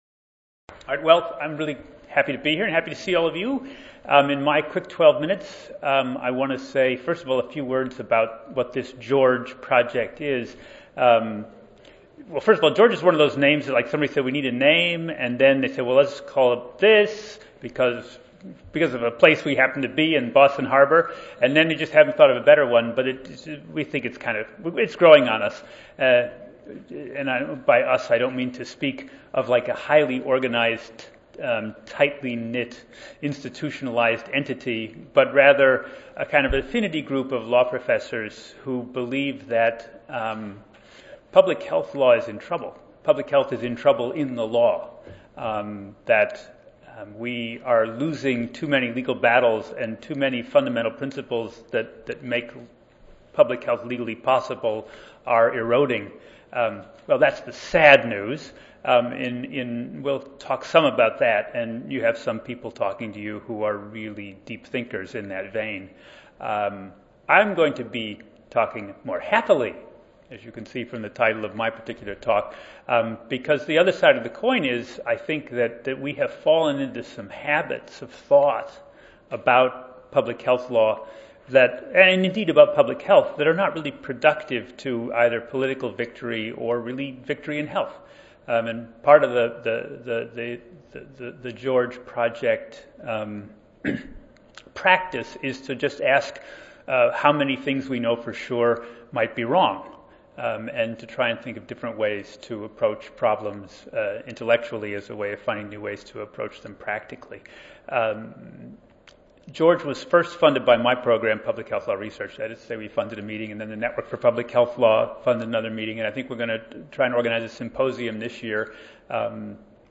3366.1 Advancing Public Health through Law: The George Project Monday, November 4, 2013: 2:30 p.m. - 4:00 p.m. Oral This Panel will discuss the deterioration of the legal environment for public health law, the role that legal academics can play in securing a legal climate that is more supportive of population health, as well as the barriers that public health legal scholar’s face in such efforts.